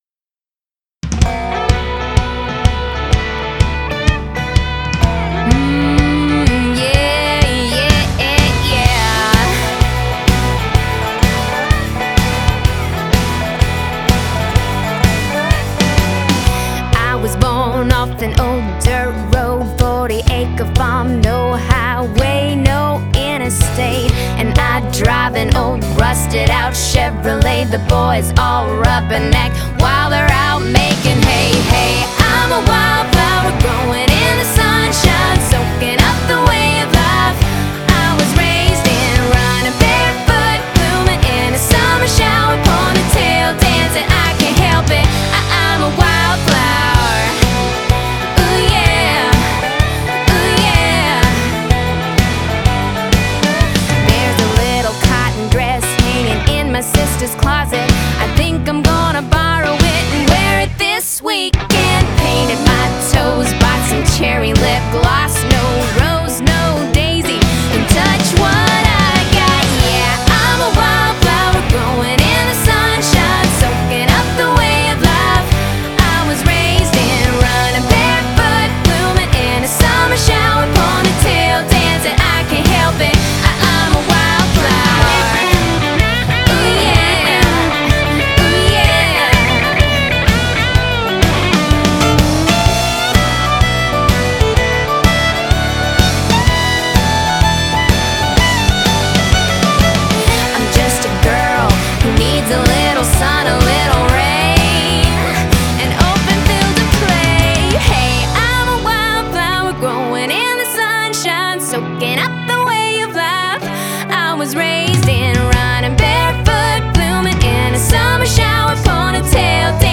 американский кантри-дуэт
вокал, мандолина, скрипка
вокал, гитара